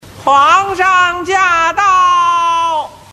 皇上驾到音效_人物音效音效配乐_免费素材下载_提案神器